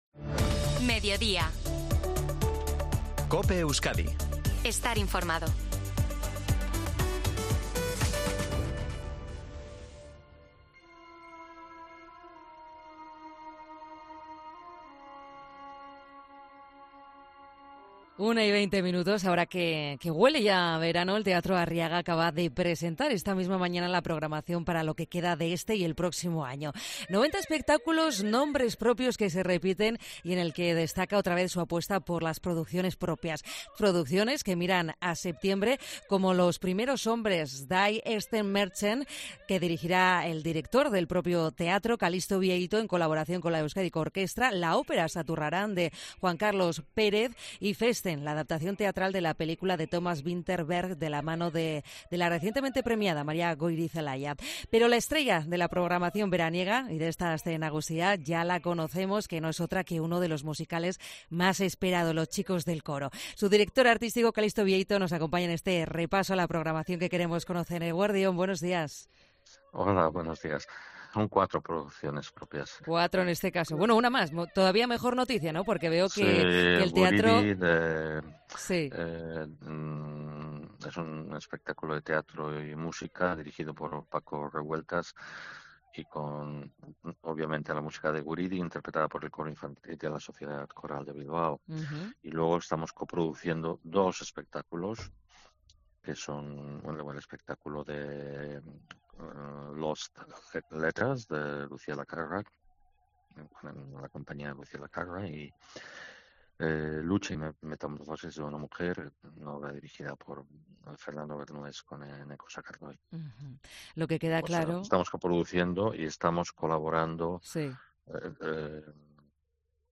Entrevista a Calixto Bieito, director Teatro Arriaga